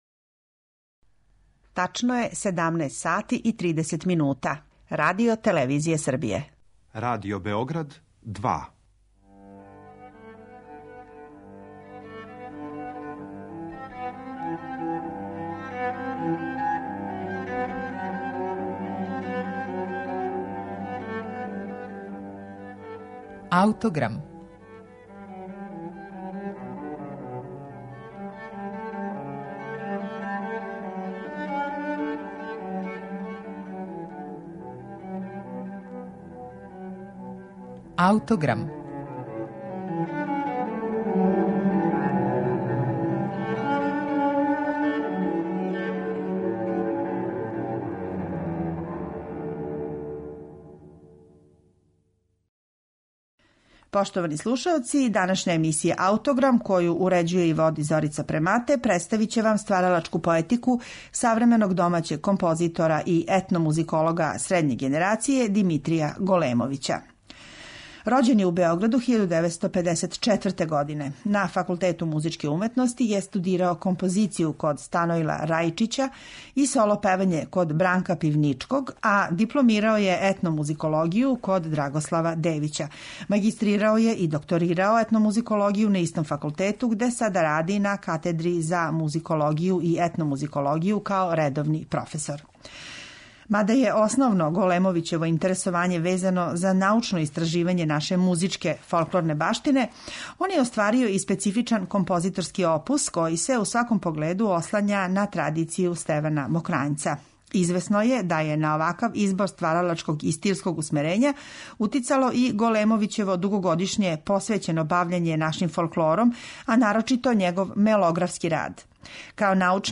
ораторијум
сопран
баритон
мушки хор